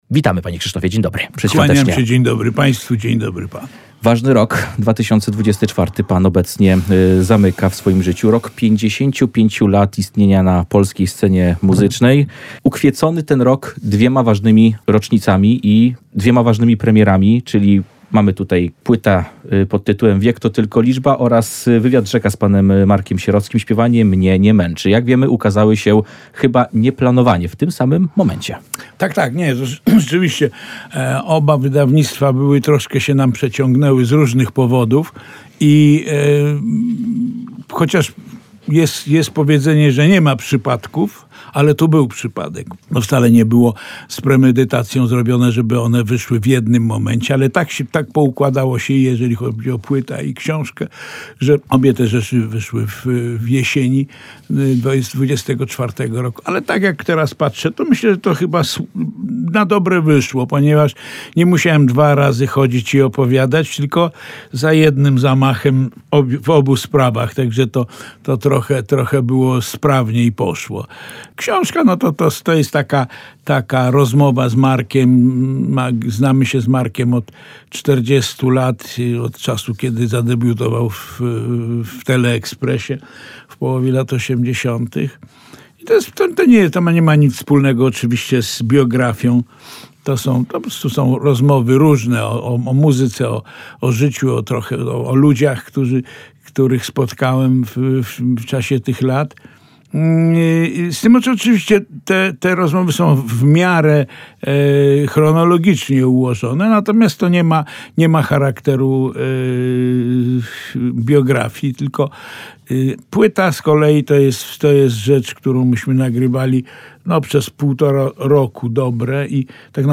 Krzysztof Cugowski - "Czas jest nieubłagany" [POSŁUCHAJ WYWIADU]